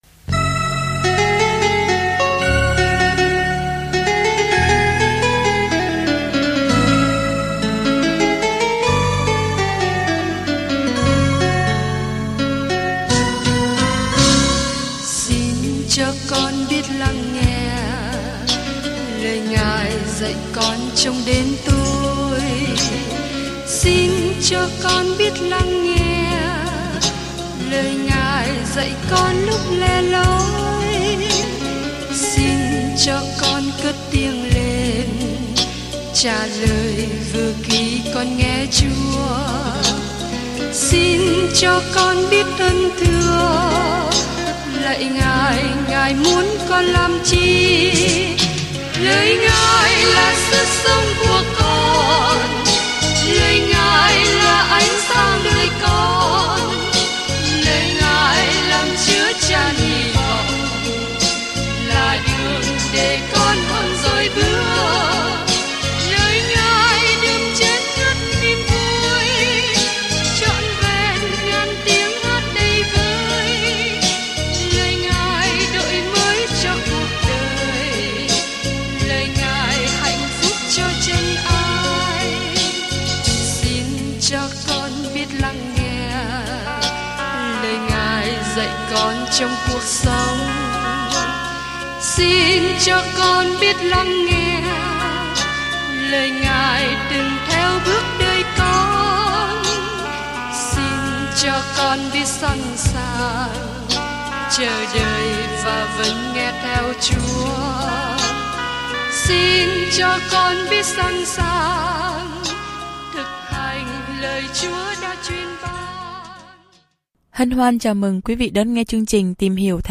Kinh Thánh I Các Vua 15 I Các Vua 16 Ngày 8 Bắt đầu Kế hoạch này Ngày 10 Thông tin về Kế hoạch Sách Các Vua tiếp tục câu chuyện về vương quốc Y-sơ-ra-ên thịnh vượng dưới thời Đa-vít và Sa-lô-môn nhưng cuối cùng lại tan rã. Du hành hàng ngày qua 1 Các Vua khi bạn nghe nghiên cứu âm thanh và đọc những câu chọn lọc từ lời Chúa.